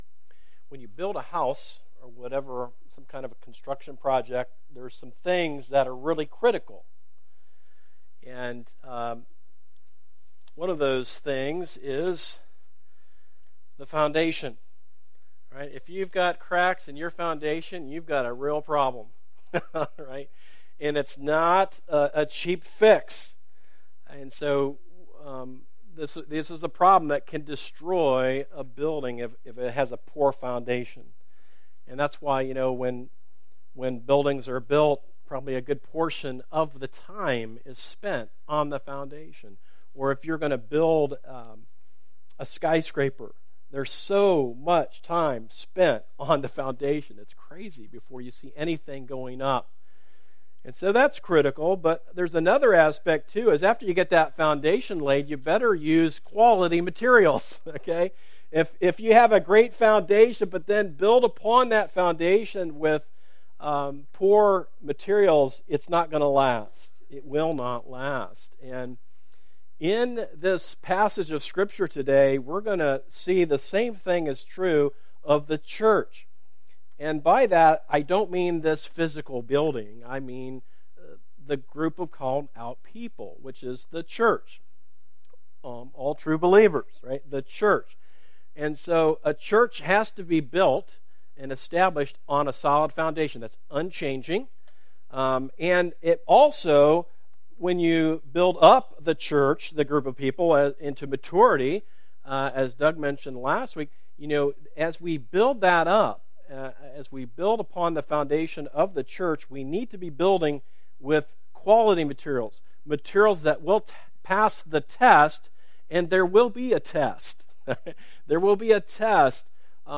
A message from the series "Church Matters."